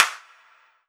Sf Clap.wav